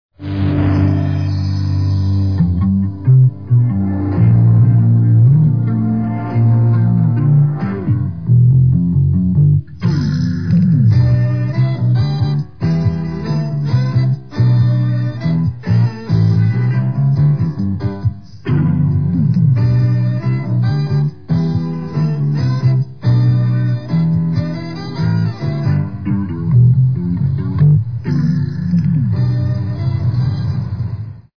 Opening theme.